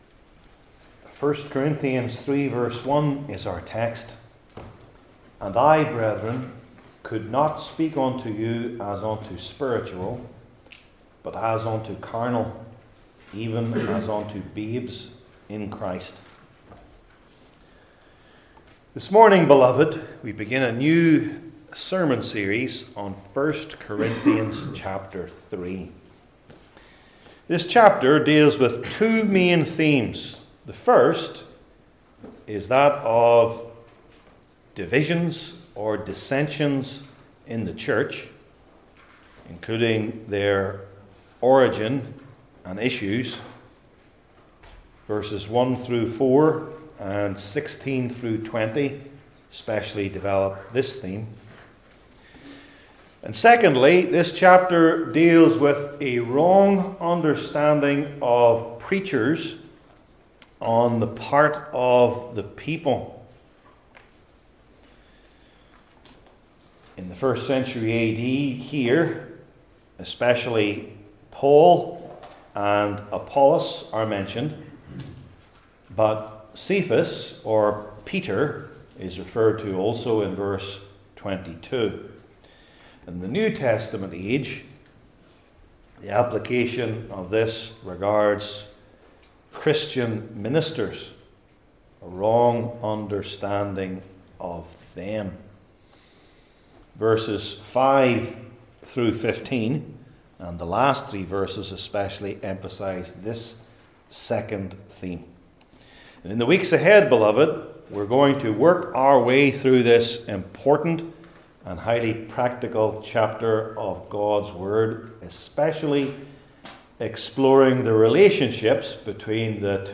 Church Divisions and Christian Ministers Passage: I Corinthians 3:1 Service Type: New Testament Individual Sermons I. The Issues II.